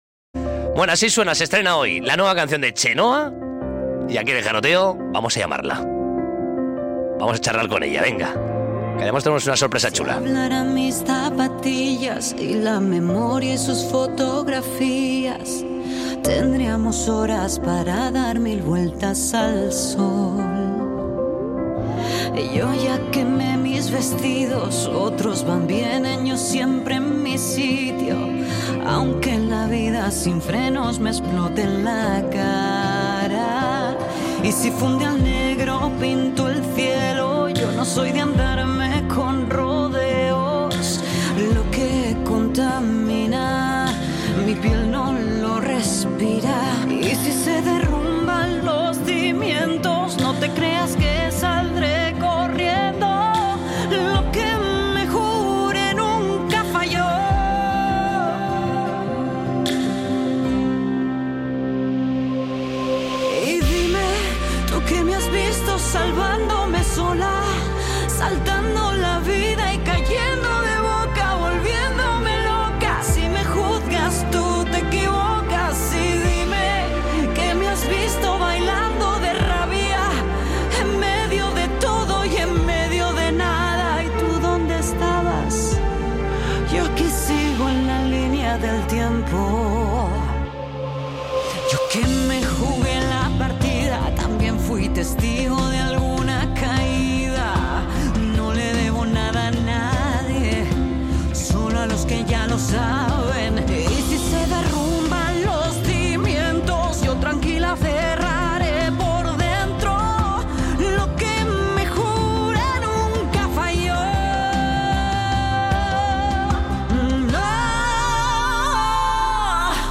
Hablamos por teléfono con Chenoa para presentarnos lo que será su nuevo single La Línea Del Tiempo.
entrevista-chenoa-tel-online-audio-convertercom.mp3